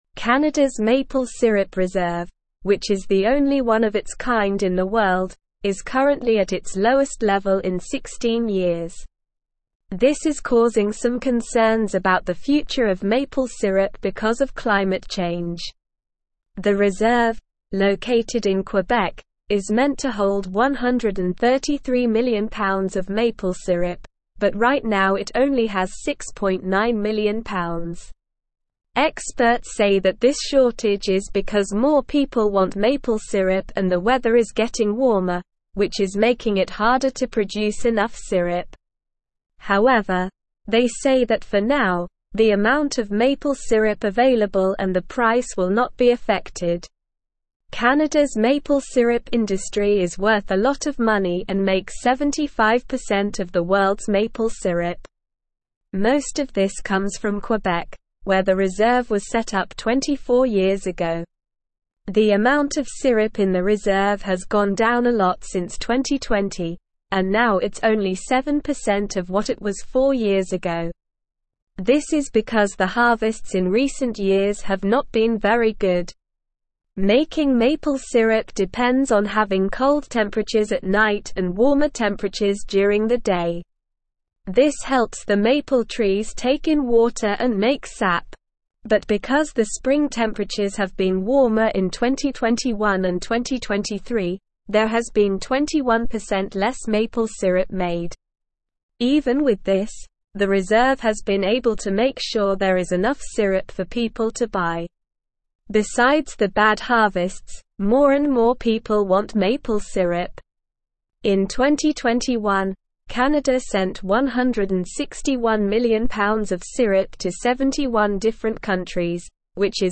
Slow
English-Newsroom-Upper-Intermediate-SLOW-Reading-Canadas-Maple-Syrup-Reserve-Reaches-16-Year-Low.mp3